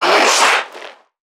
NPC_Creatures_Vocalisations_Infected [110].wav